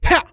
XS发力01.wav
XS发力01.wav 0:00.00 0:00.32 XS发力01.wav WAV · 28 KB · 單聲道 (1ch) 下载文件 本站所有音效均采用 CC0 授权 ，可免费用于商业与个人项目，无需署名。